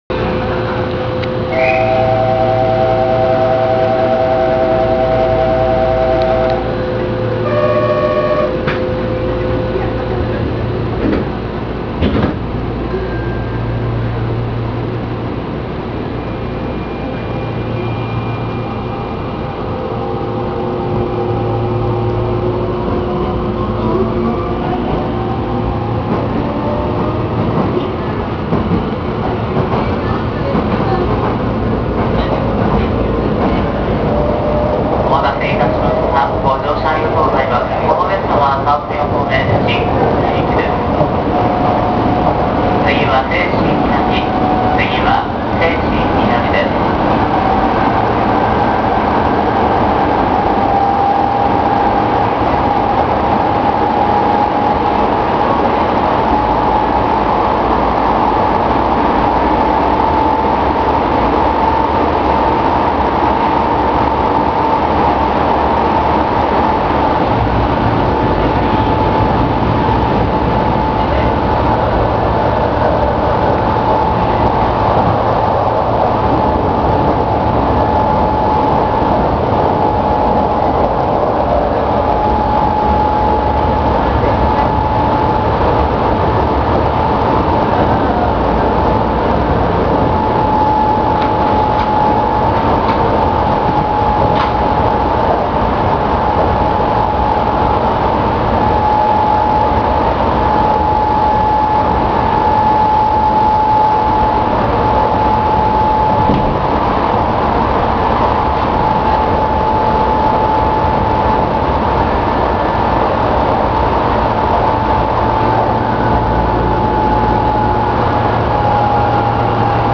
・1000形（日立GTO）走行音
【西神延伸線】西神中央〜西神南（2分44秒：891KB）
日立のGTOということで、西武6000系、京王8000系、東急2000系などで聞ける走行音に類似しています。何とも見た目と釣り合わない走行音ですが、IGBTの車両と比べたらまだ違和感も少ないはず。